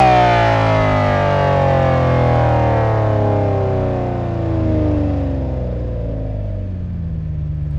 v12_08_decel.wav